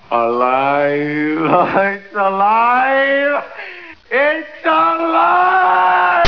TVYNGFRANK: Gene Wilder from young franketstien Screaming, IT"S ALIVE!".